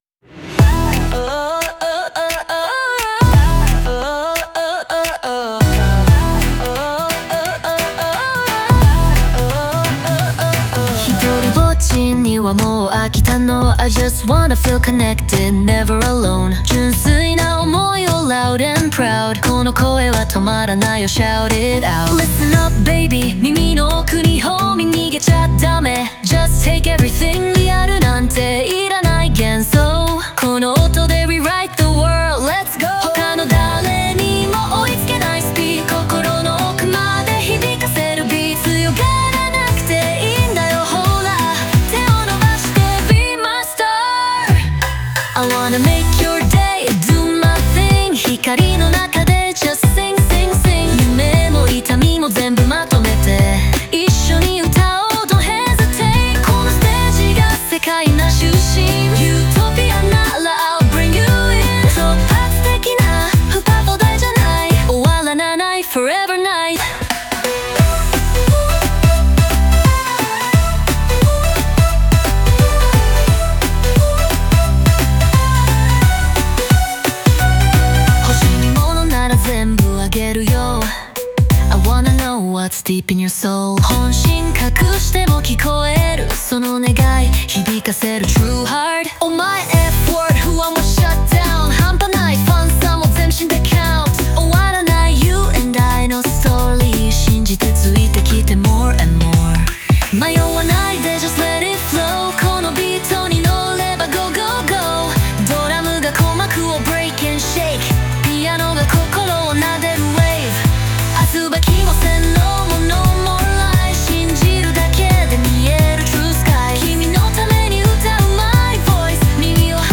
オリジナル曲♪
この歌詞は、孤独を抜け出し「繋がり」を求める強い願いを、力強いビートと共に解き放つストーリーです。
英語を織り交ぜることで、感情の勢いとリズム感を加え、リスナーを引き込むライブ感を意識しました。